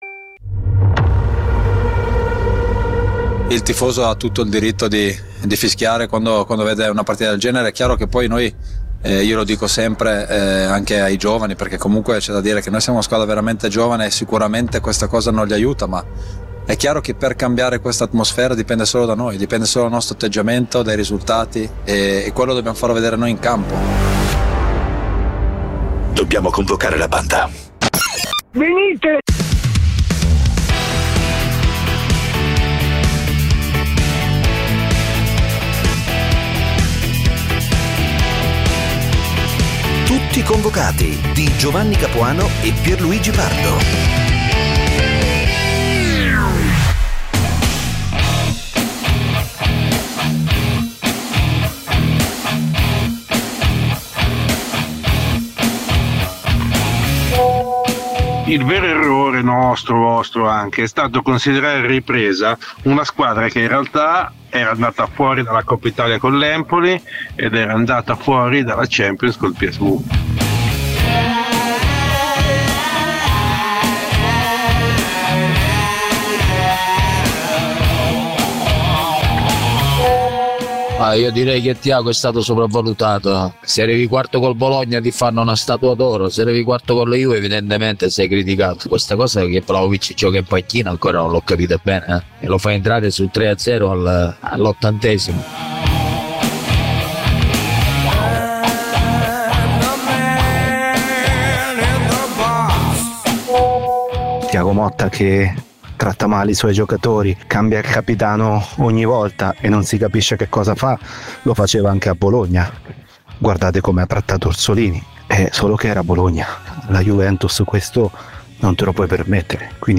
Le voci e i suoni del calcio. Una lettura ironica e coinvolgente degli avvenimenti dell'attualità sportiva, senza fanatismi e senza tecnicismi. Commenti, interviste e soprattutto il dibattito con gli ascoltatori, che sono Tutti convocati.
Con imitazioni, tic, smorfie, scherzi da spogliatoio e ironia irriverente.